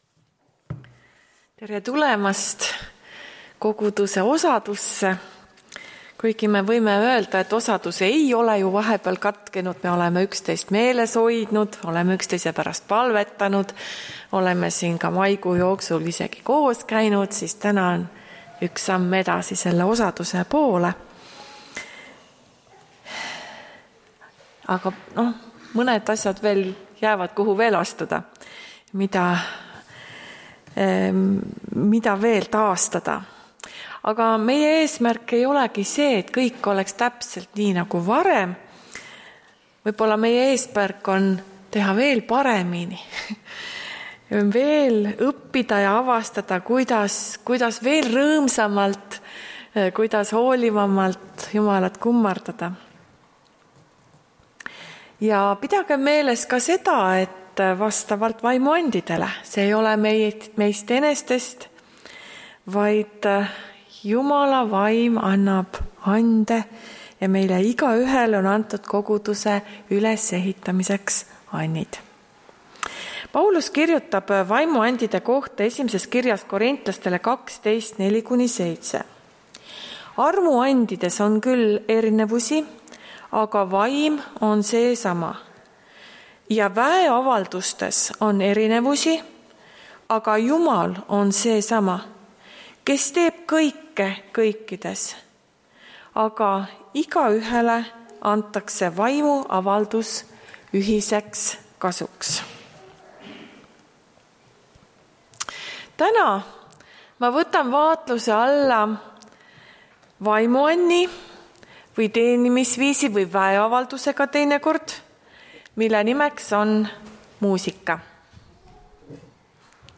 Tartu adventkoguduse 05.06.2021 hommikuse teenistuse jutluse helisalvestis